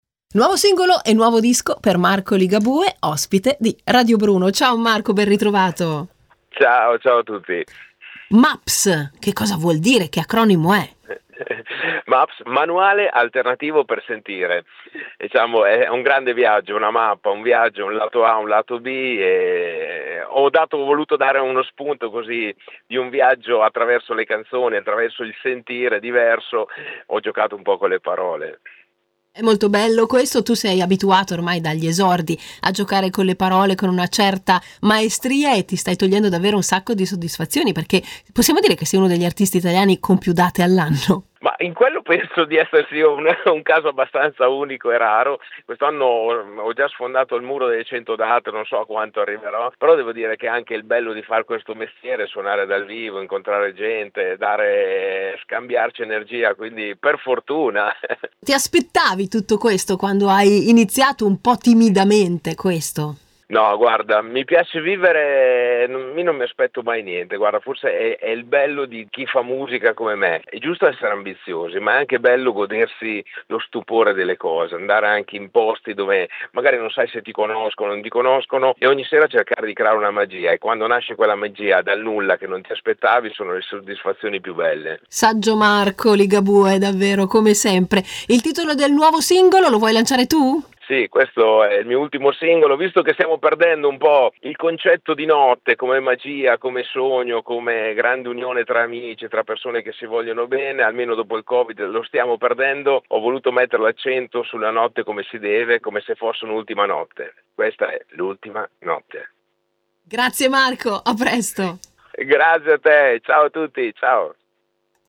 Marco Ligabue è stato ospite a Radio Bruno, intervistato